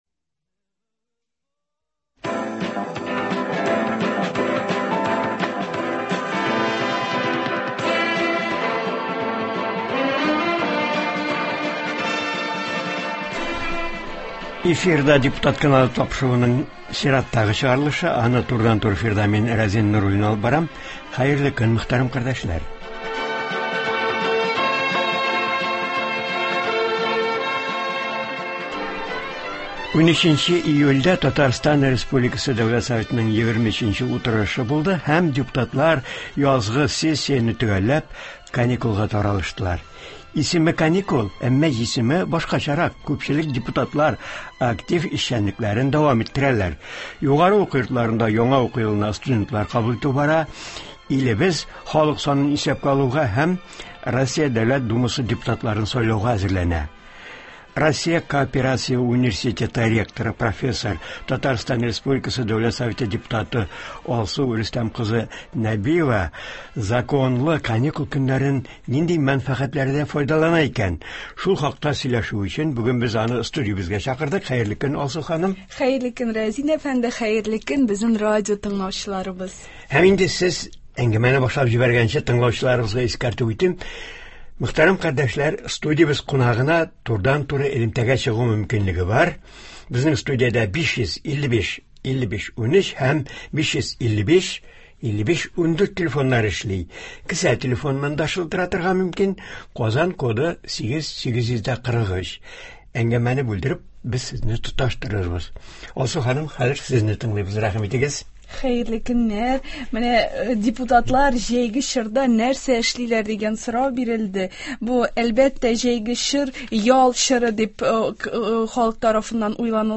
Әмма алар чираттагы ялдабыз дип кул кушырып утырмыйлар, эшчәнлекләрен дәвам иттерәләр, Россия Дәүләт Думасы депутатларын сайлауга, халык санын исәпкә алуга әзерләнәләр. Россия кооперация университеты һәм Казан кооперация институты ректоры, профессор, Татарстан республикасы Дәүләт Советы депутаты Алсу Нәбиева турыдан-туры эфирда шул хакта сөйләячәк һәм тыңлаучылар сорауларына җавап бирәчәк.